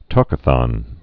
(tôkə-thŏn)